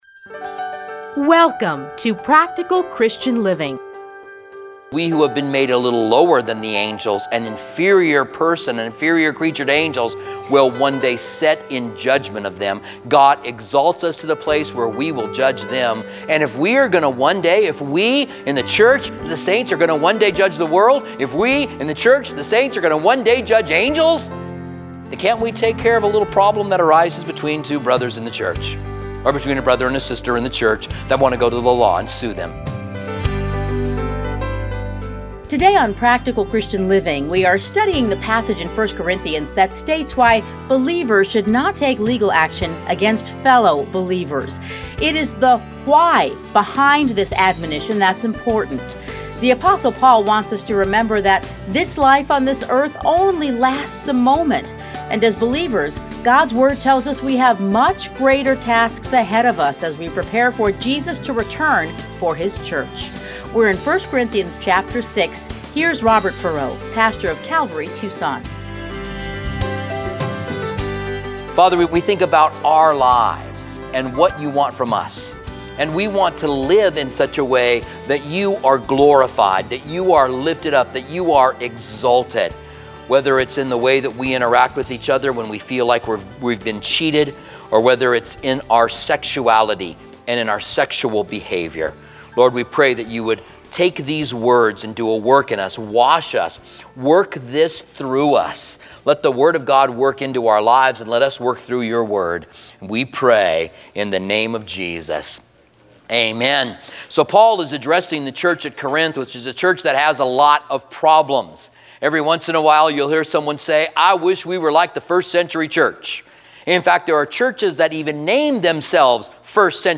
Listen here to a teaching from 1 Corinthians.